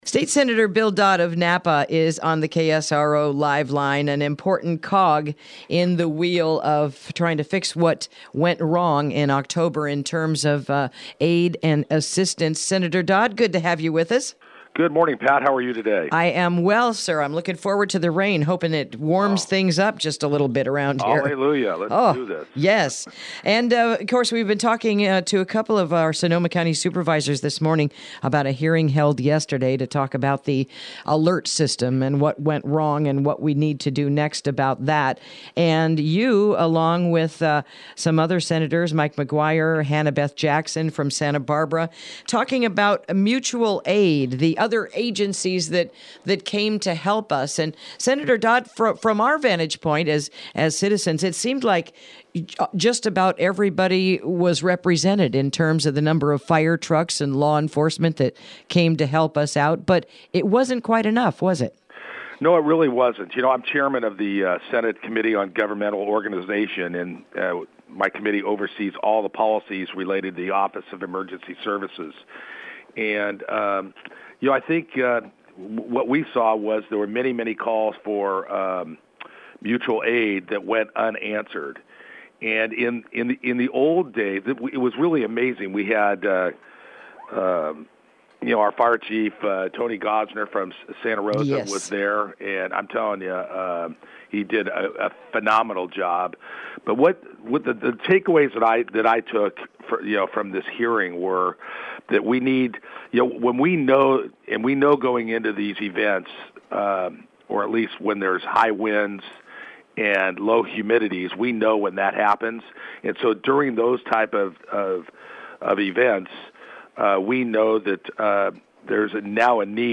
Interview: Highlights and Issues Discussed at the Recent Wildfires Hearing